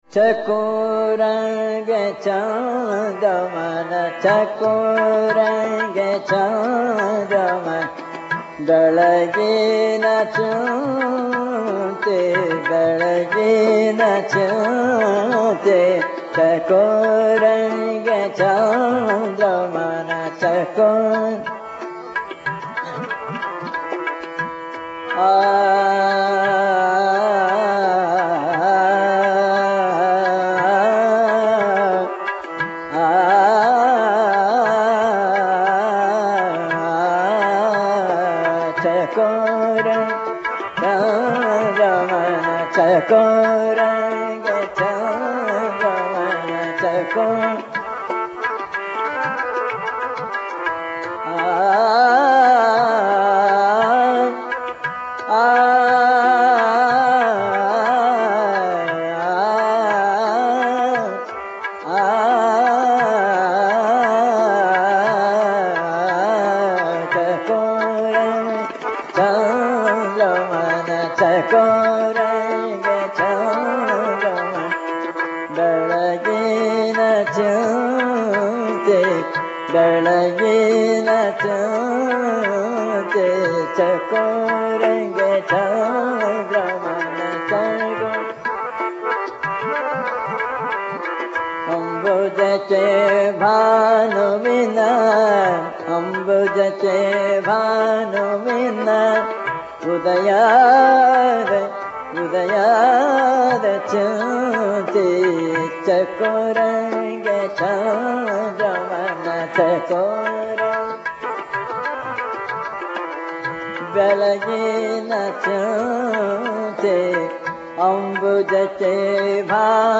Bhairavi (Part 1/2) » Rajan Parrikar Music Archive
Basavanna‘s vacana is tuned and rendered by Basavraj Rajguru: